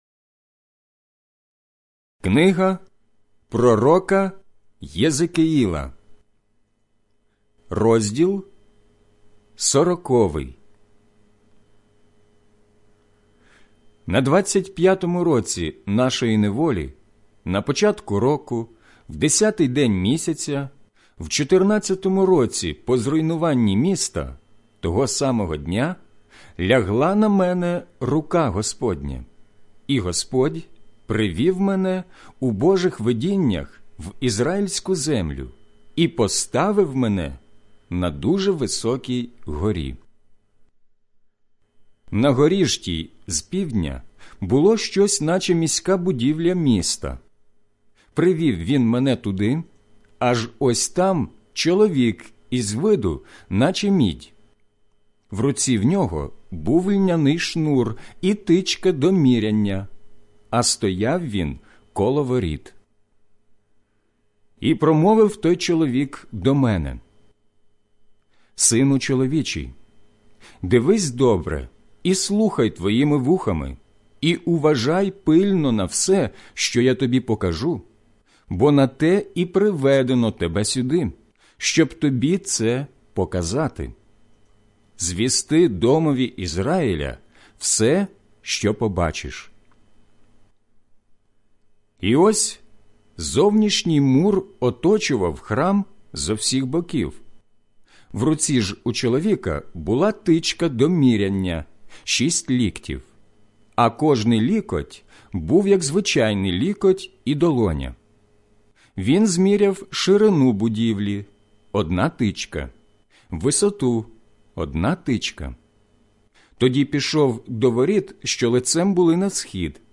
Інші статті за темами СЮЖЕТ аудіобіблія ПЕРСОНА МІСЦЕ ← Натисни «Подобається», аби читати CREDO в Facebook